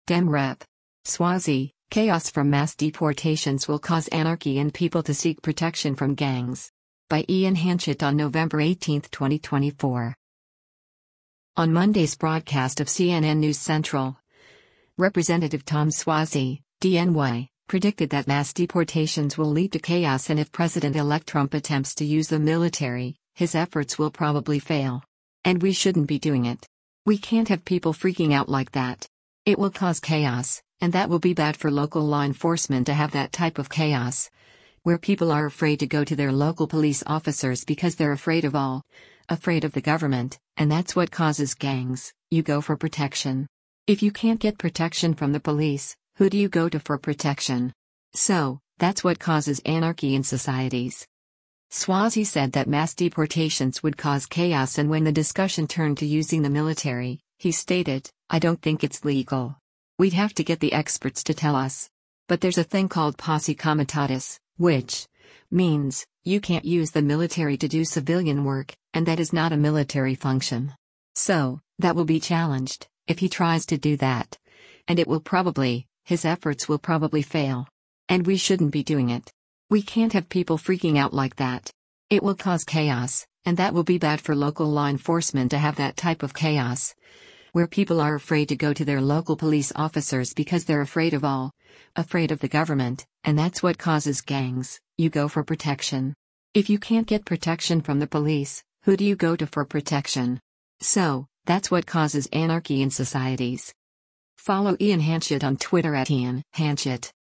On Monday’s broadcast of “CNN News Central,” Rep. Tom Suozzi (D-NY) predicted that mass deportations will lead to “chaos” and if President-Elect Trump attempts to use the military, “his efforts will probably fail.